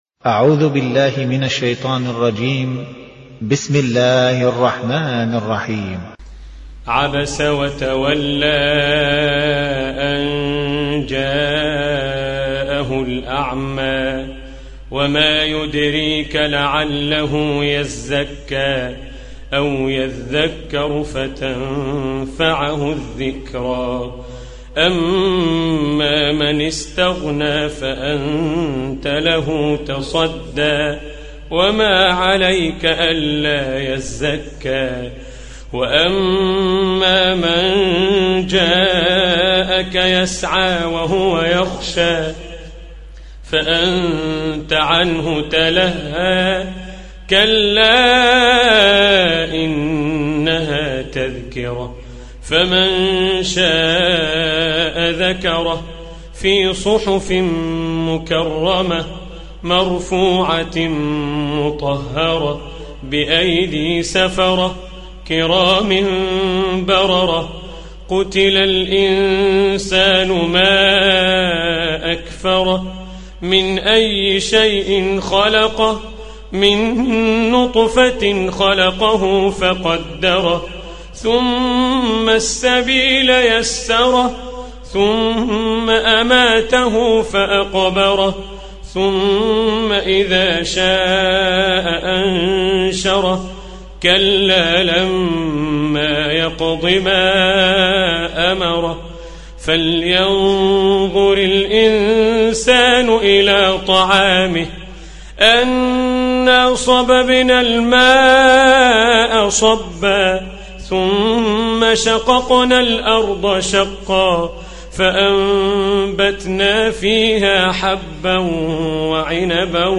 Surah Repeating تكرار السورة Download Surah حمّل السورة Reciting Murattalah Audio for 80. Surah 'Abasa سورة عبس N.B *Surah Includes Al-Basmalah Reciters Sequents تتابع التلاوات Reciters Repeats تكرار التلاوات